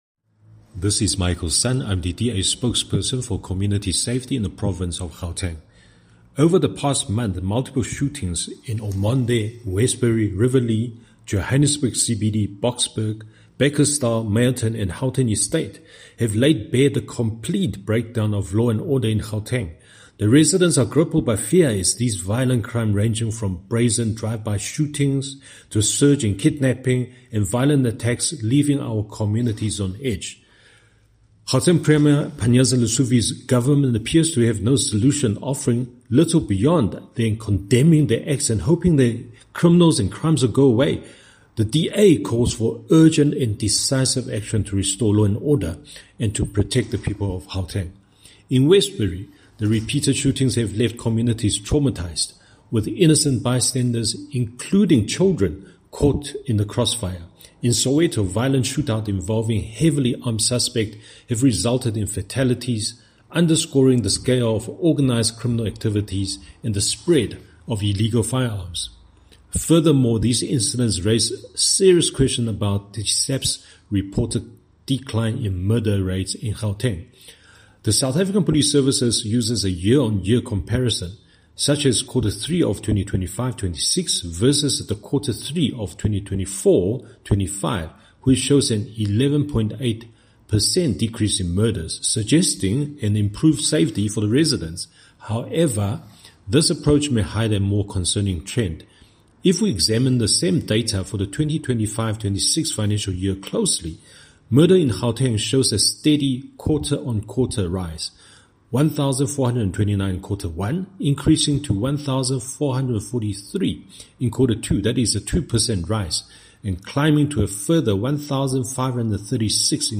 Note to Editors: Please find a soundbite in English from DA MPL, Michael Sun